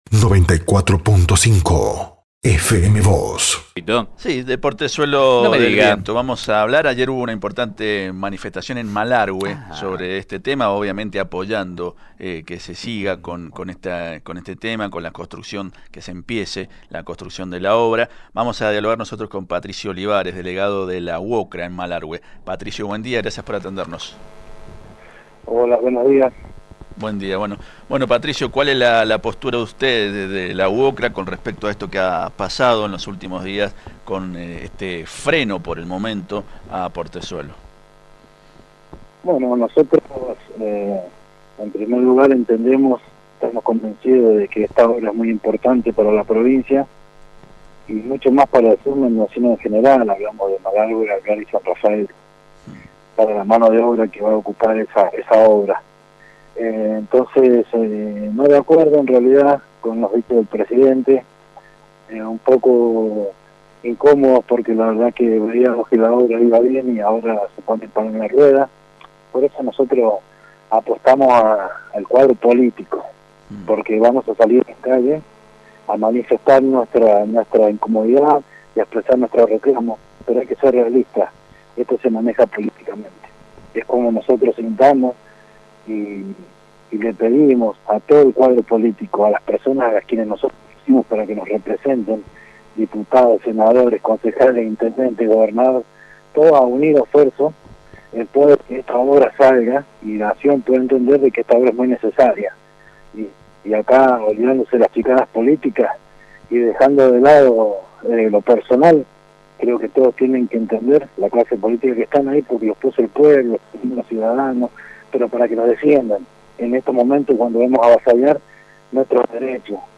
Desde FM Vos (94.5) y Diario San Rafael dialogamos